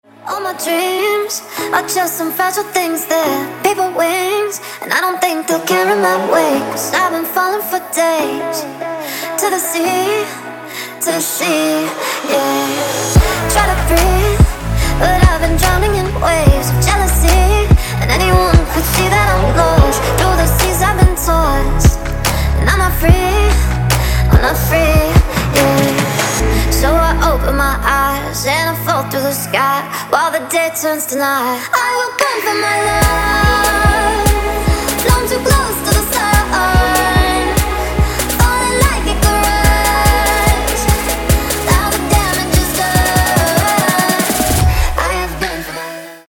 женский вокал
dance
Electronic
EDM
club
vocal